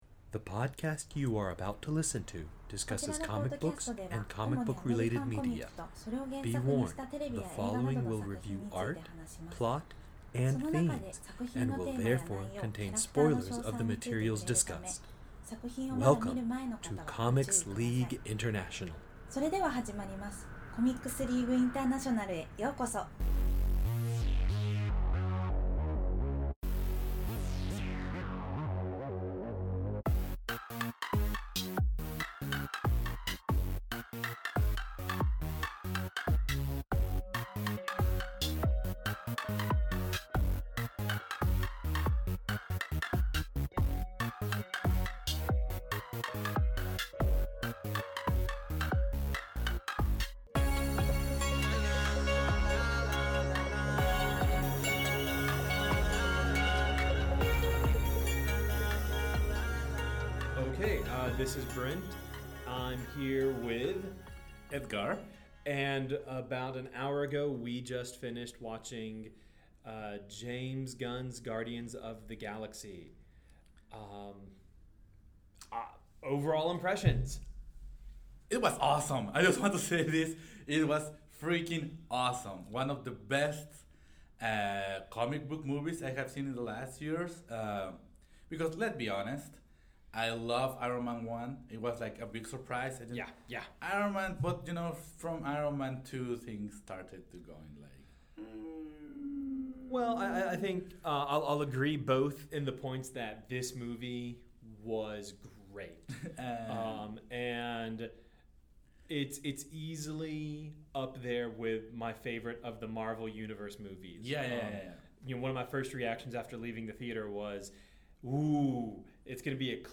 CLI’s intro and outro music